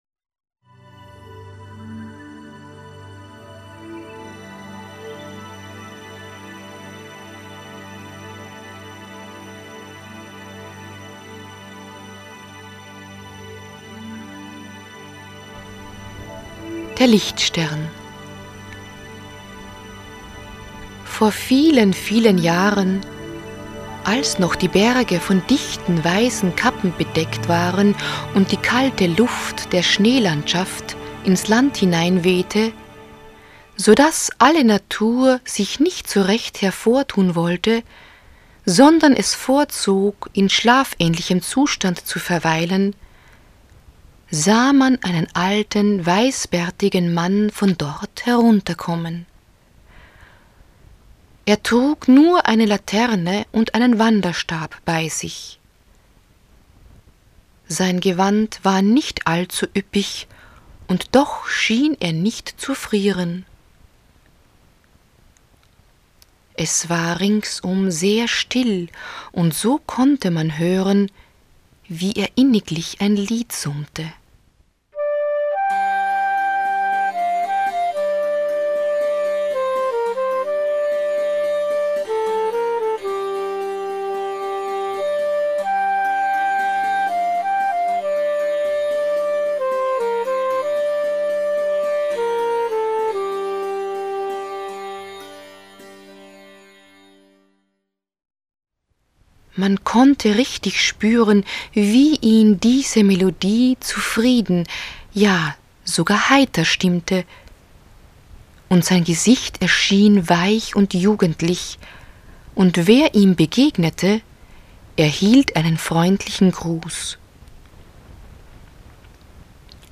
Arrangiert und aufgenommen wurden sie in den SAI-Studios.